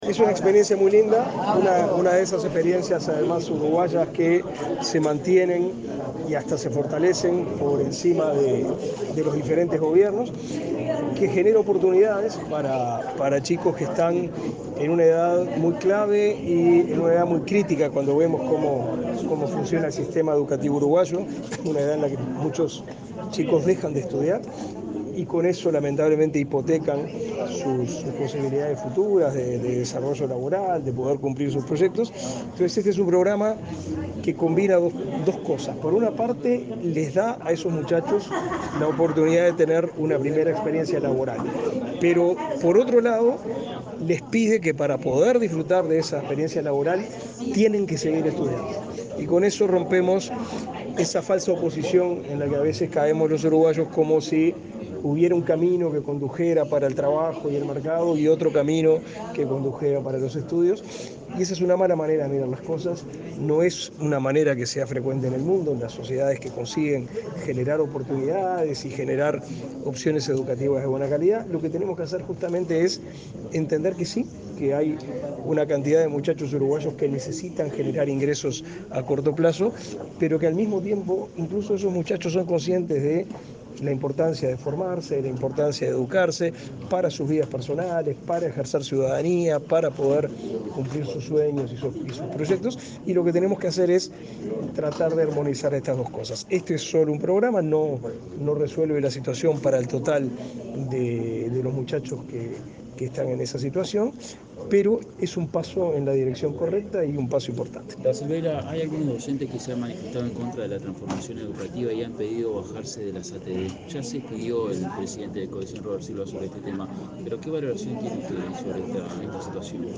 Declaraciones del ministro de Educación y Cultura, Pablo da Silveira
Declaraciones del ministro de Educación y Cultura, Pablo da Silveira 26/10/2022 Compartir Facebook X Copiar enlace WhatsApp LinkedIn El ministro de Educación y Cultura, Pablo da Silveira, dialogó con la prensa luego de participar de la presentación en la 11.ª edición del programa Yo Estudio y Trabajo.